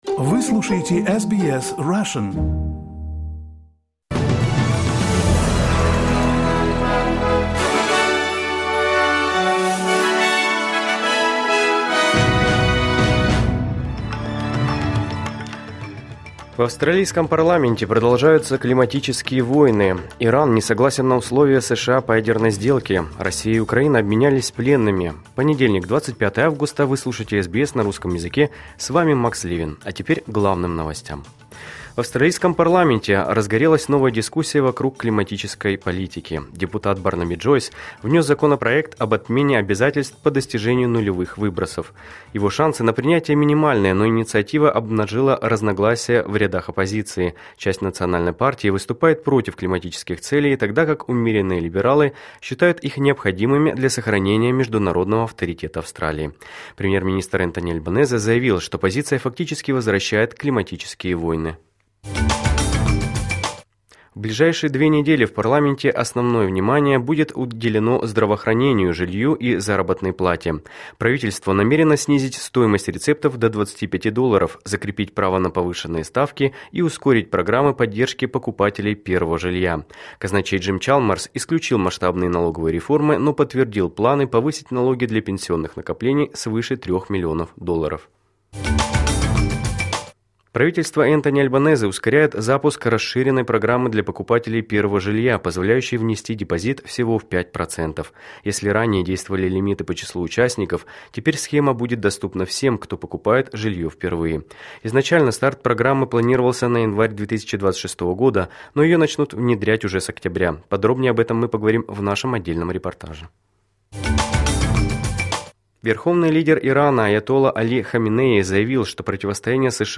Новости SBS на русском языке — 25.08.2025